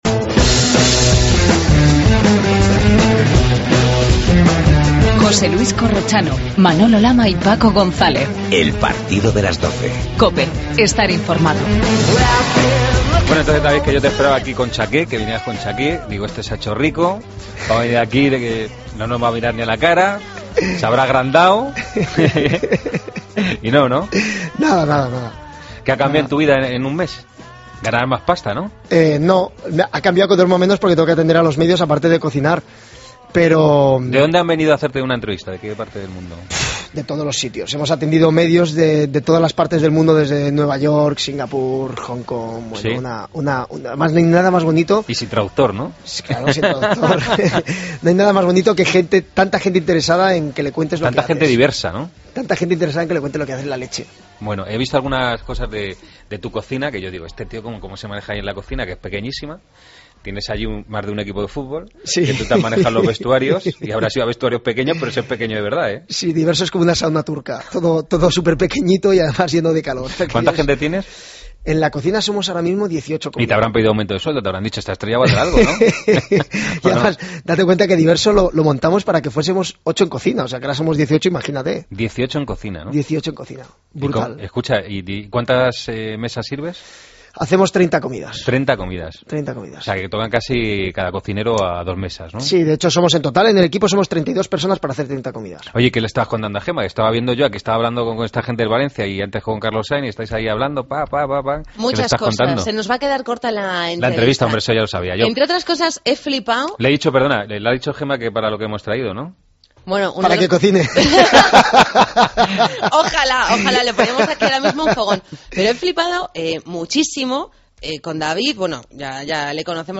Entrevista a David Muñoz, en El Partido de las 12